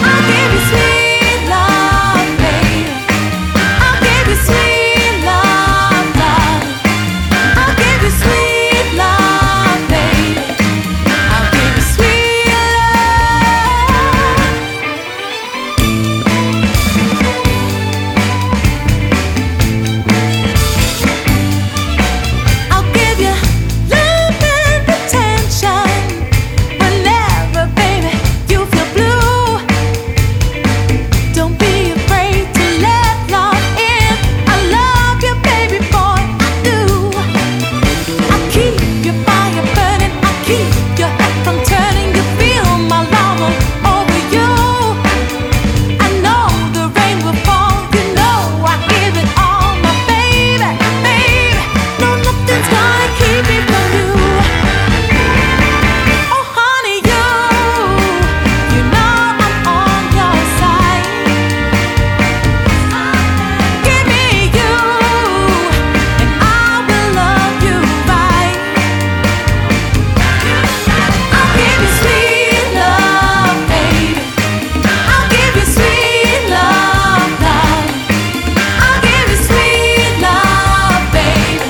カルト和モノ・ディスコ・ブギー12インチ！
キラキラしたギター・カッティングやパーカッシヴなアレンジも効いた